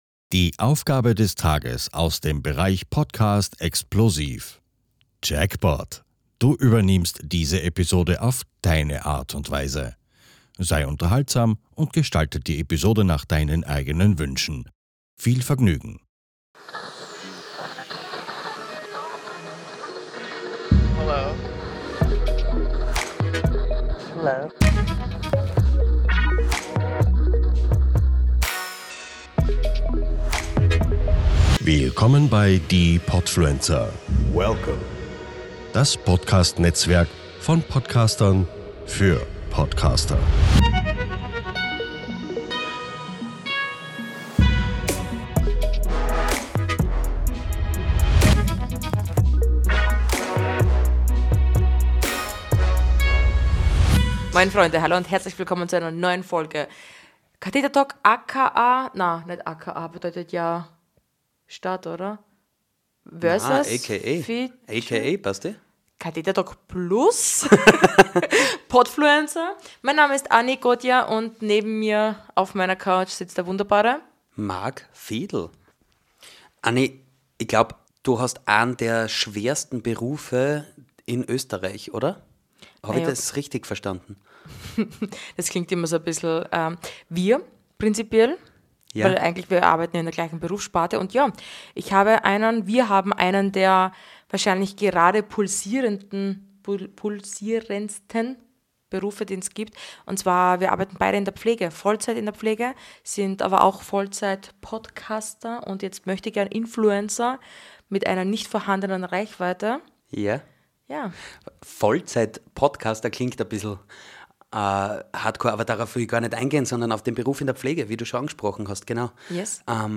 Um das Ganze etwas aufzulockern, probieren sie sich in dieser besonderen Folge an einer extra Portion Satire. Sie beleuchten humorvoll, wie der Pflegealltag und das Berufsbild garantiert nicht sein sollten! Achtung: Humorvolle Episode!